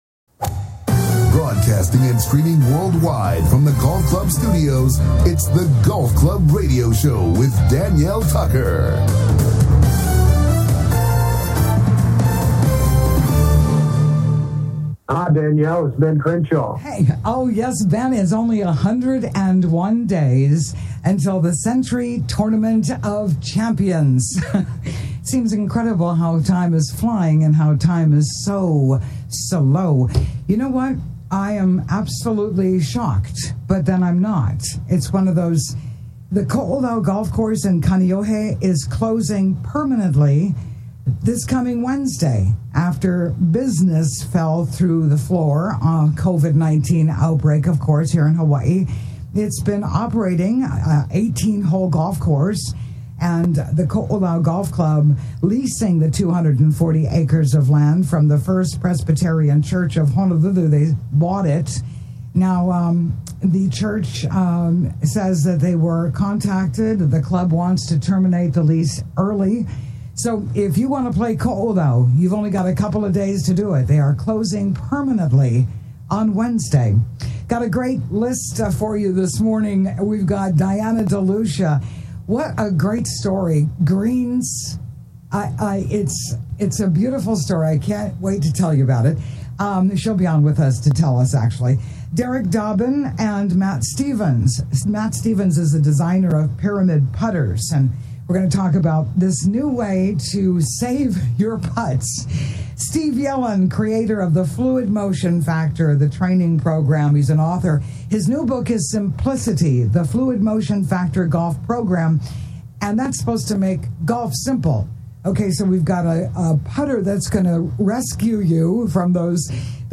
Golf Club Radio Show